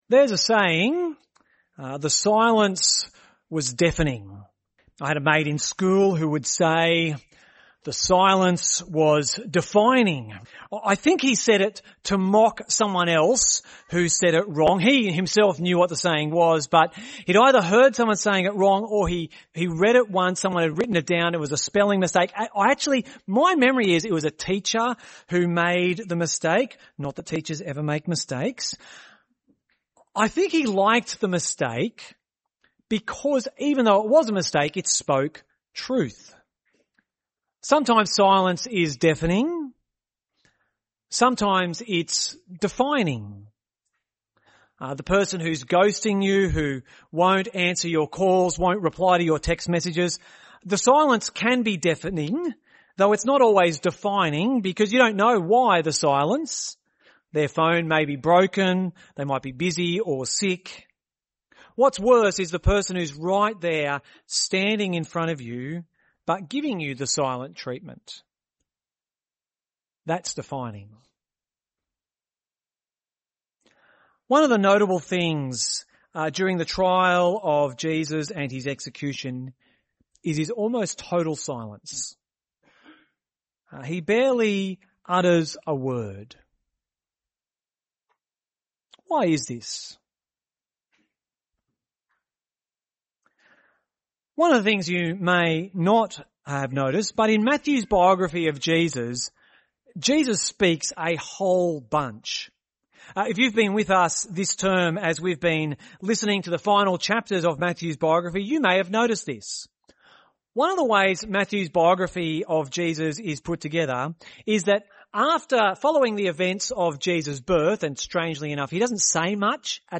A Good Friday message, exploring the silence of Jesus in his trial and on the cross.